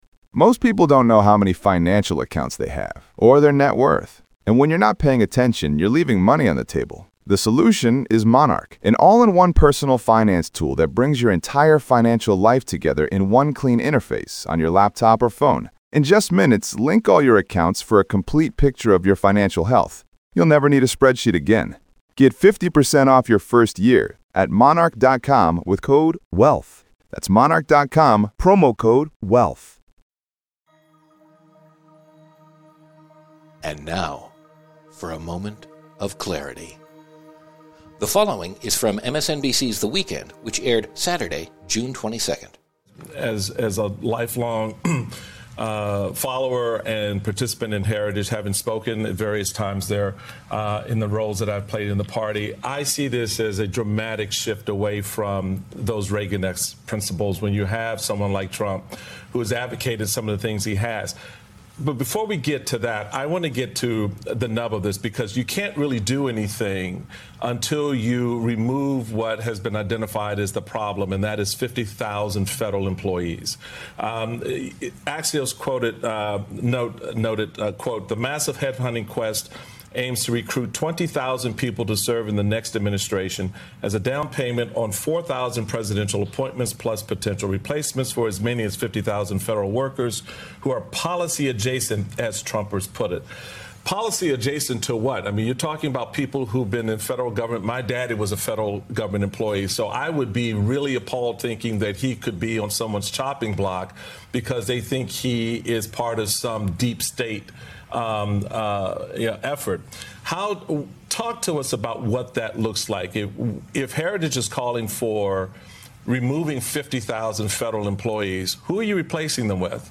Michael Steele speaks with Heritage Foundation President, Kevin Roberts, about Project 2025's plan to fire up to 50,000 federal government workers.